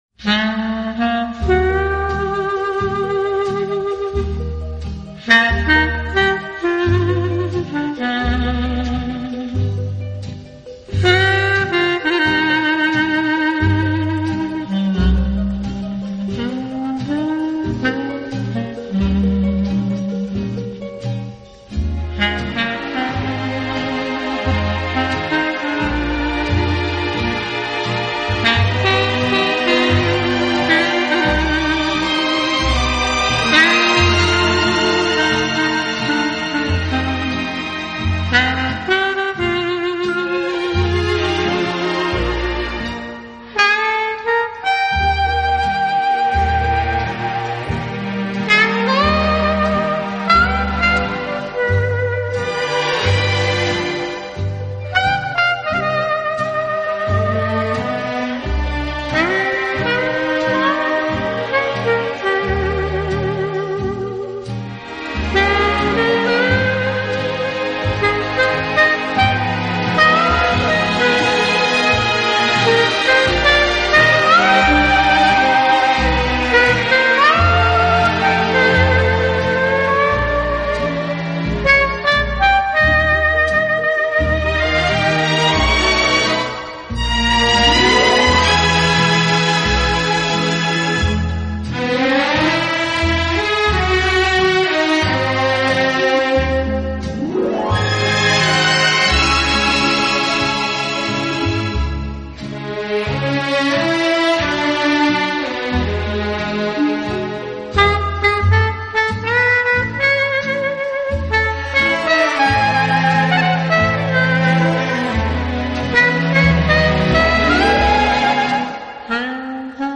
说真的，在听这张专辑以前一直没觉得单簧管可以唱出如此动听浪漫的旋律。